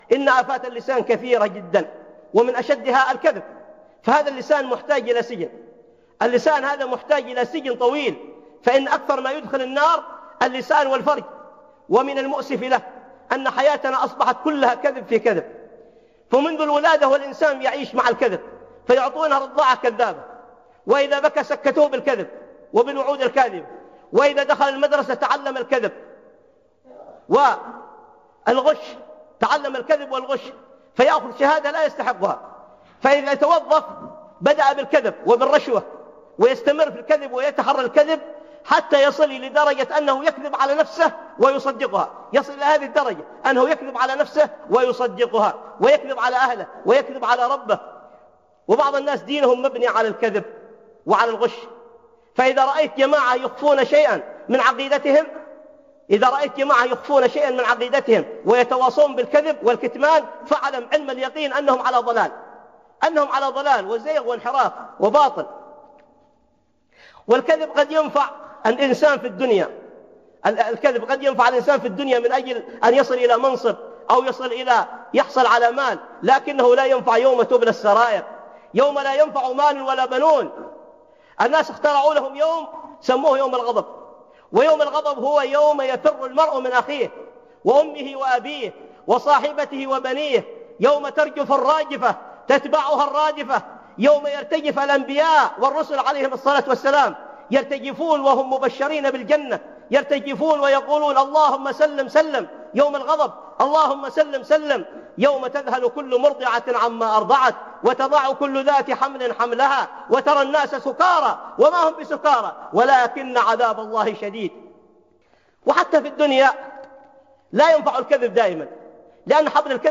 أصبحت حياتنا كلها كذب - خطب